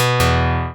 Opus versions of the sound pack.
ScreenReaderOff.opus